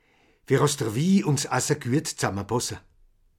Masevaux